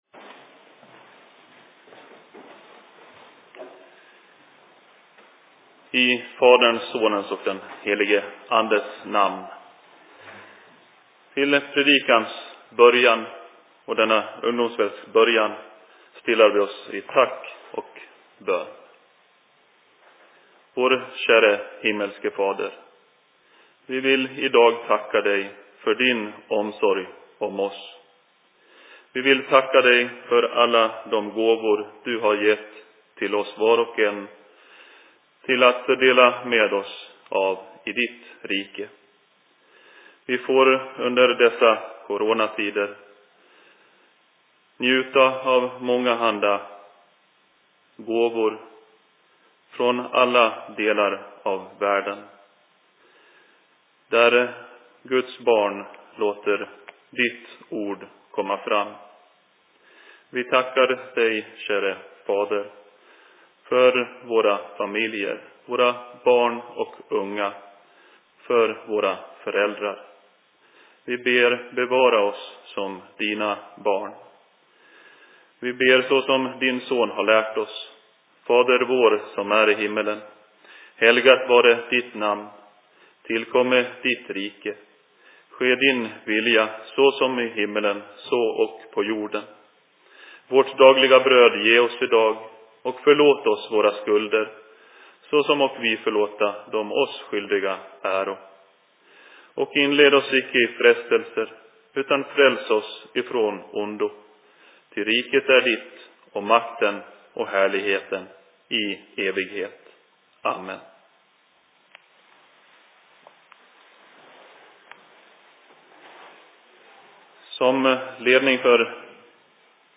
Ungdomskväll/Se Predikan I Mockfjärd Kyrka 15.05.2020 19.59